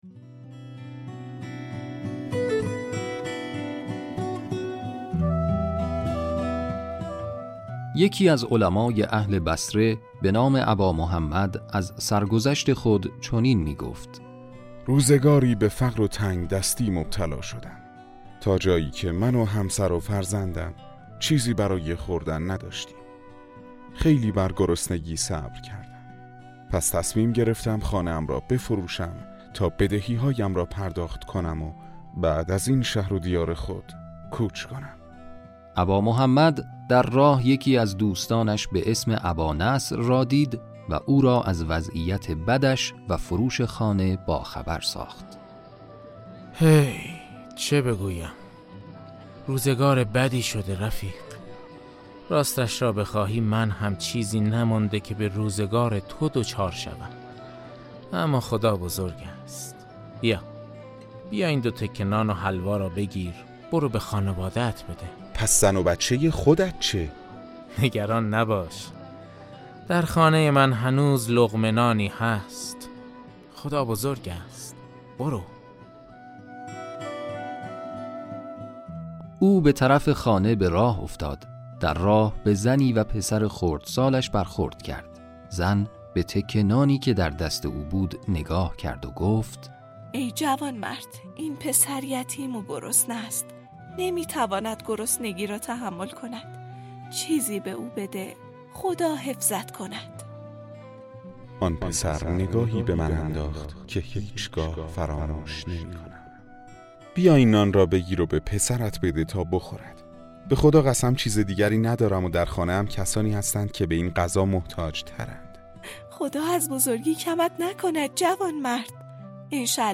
داستان صوتی کوتاه - آیا چیزی برایش باقی نمانده؟